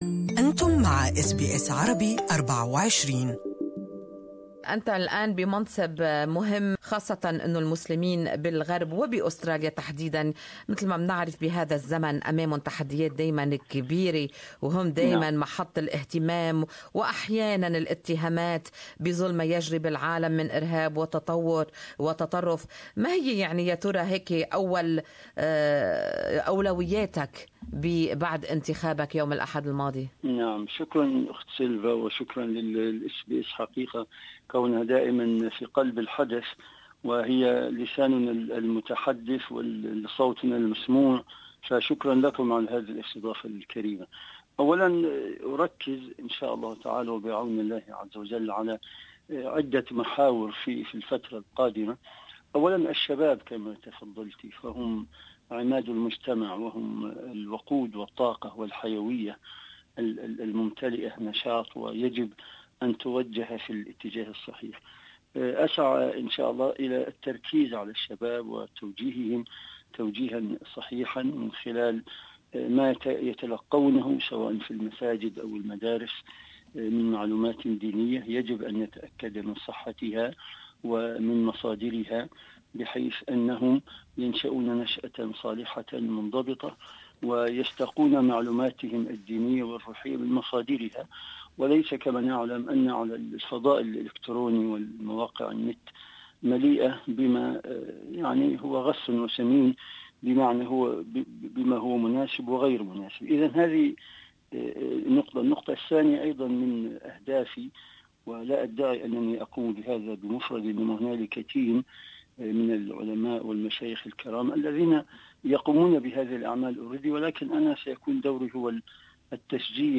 Good Morning Australia interviewed the newly elected Mufti of Australia Sheikh Abdulazim Afifi